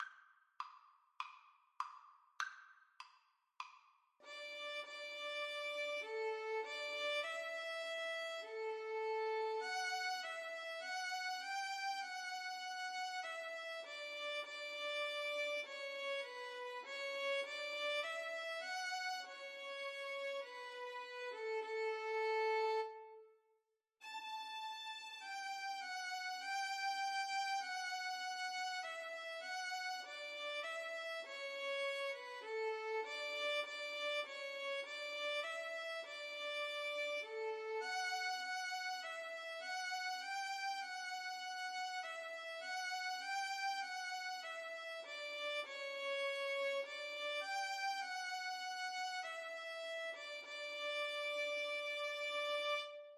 Christmas Christmas Violin Duet Sheet Music O Come All Ye Faithful
Free Sheet music for Violin Duet
Violin 1Violin 2
4/4 (View more 4/4 Music)
D major (Sounding Pitch) (View more D major Music for Violin Duet )